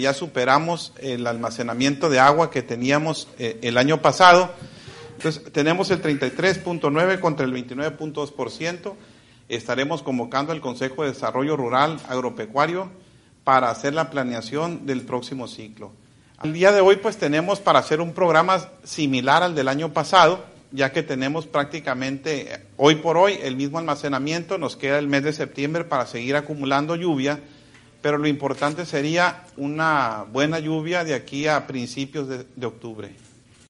Durante la conferencia Semanera encabezada por el Gobernador Rubén Rocha Moya, el funcionario estatal precisó que con lo que se tiene en embalses “nos permite ahorita ya hacer una planeación diferente a la que teníamos la semana pasada en cuanto a los cultivos y con las lluvias que se sigan generando, de acuerdo a los pronósticos, nos puede permitir en el Estado ahorrar el riego de asiento, que representa un 35% del gasto de los cultivos”, puntualizó.